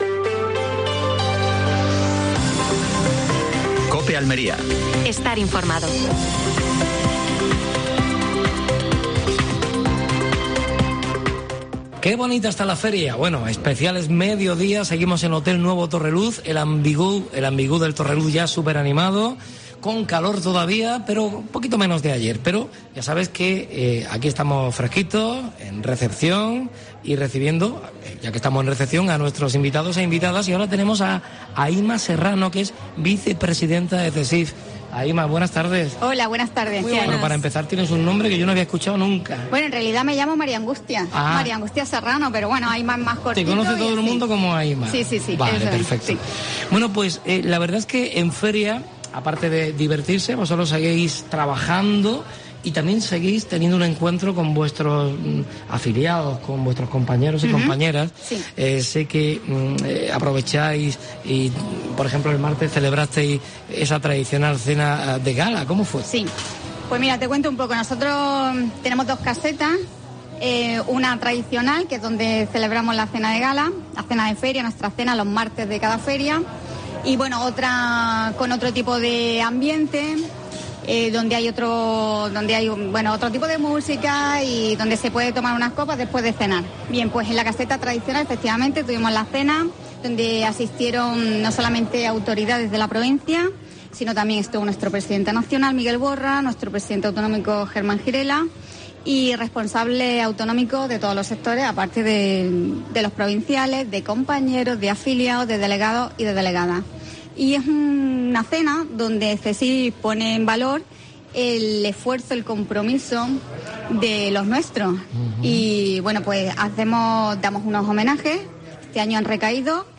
Programa especial de la Feria de Almería. Desde el Hotel Torreluz. Entrevista